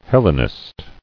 [Hel·le·nist]